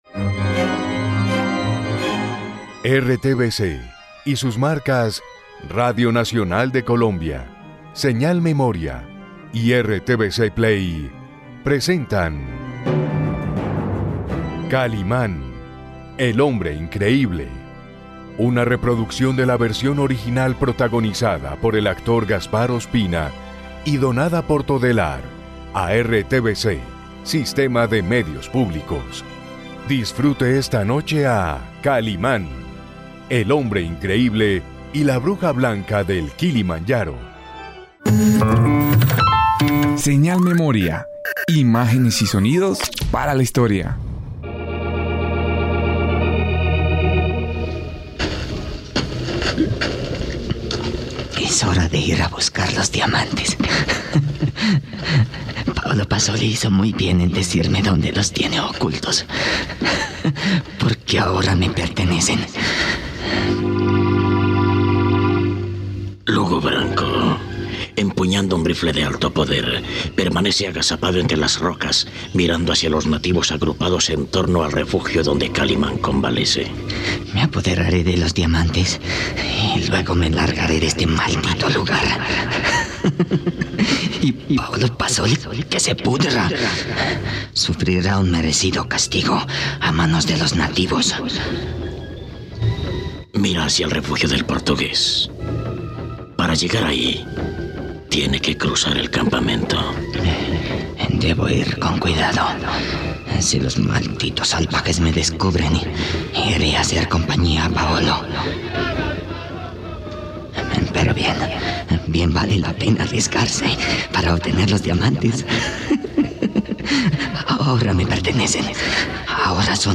Encuentra esta radionovela solo en RTVCPlay.